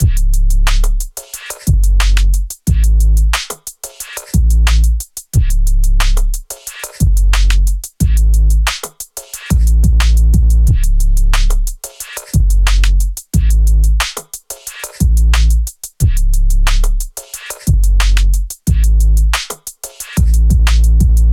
AV_Cribs_Drums_90bpm.wav